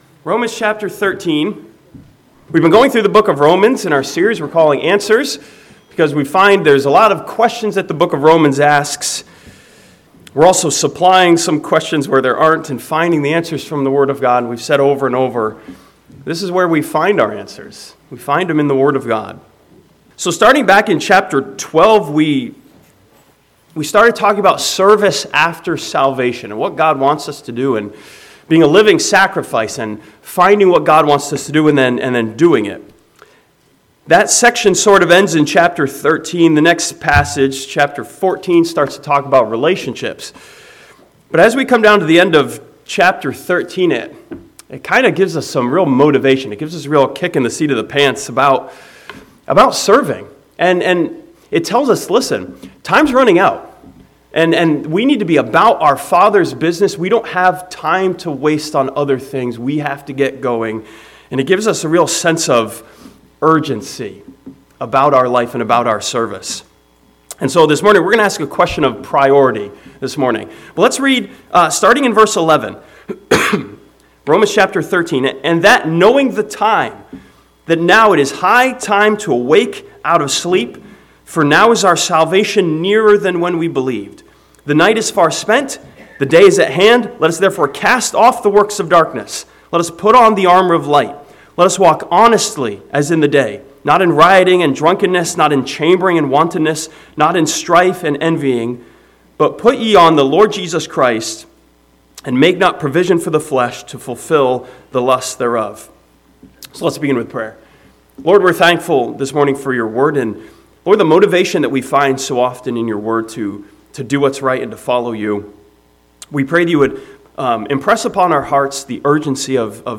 This sermon from the end of Romans chapter 13 challenges us with the urgency of the hour and asks a question of what are priority should be.